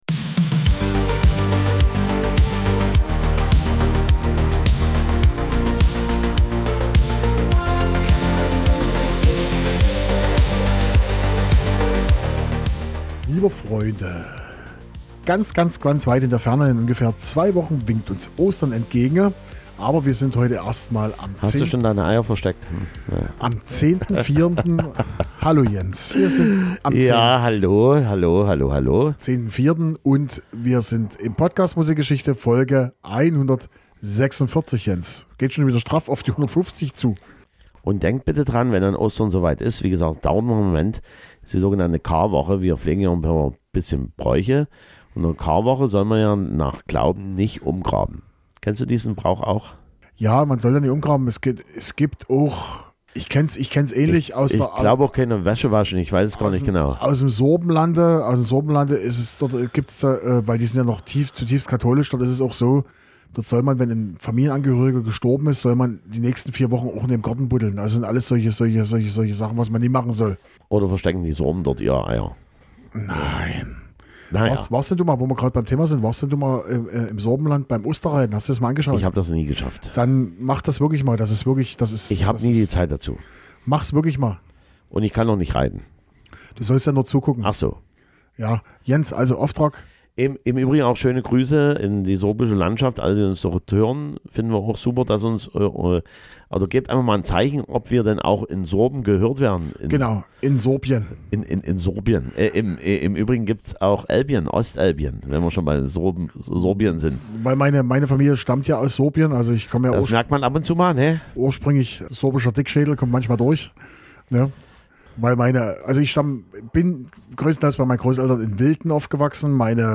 dieser gibt sich wieder sehr viel Mühe langsamer zu sprechen